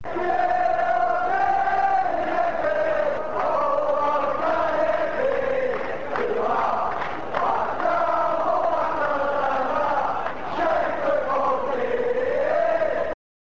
This page contains, for the moment, chants from the last game of the season at Easter Road on 8th May 1999, along with Alex McLeish's speech after the game.